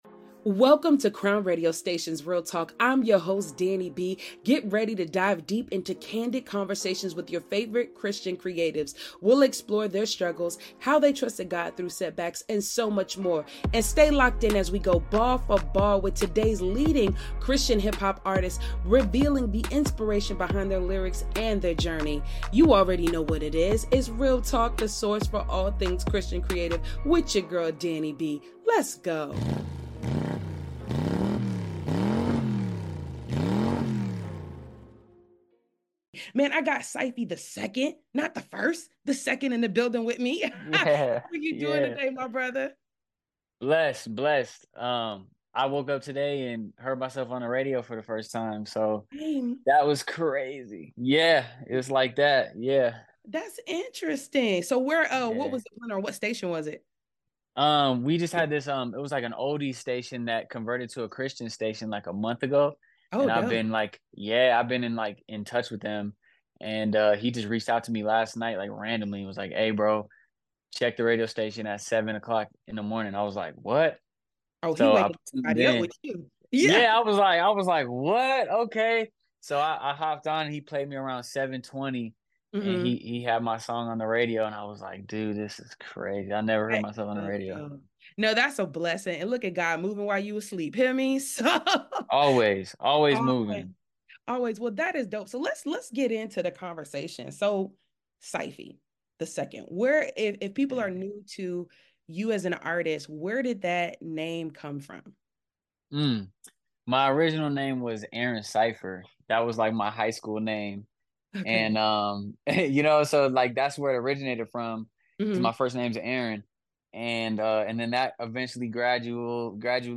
Exclusive Interview w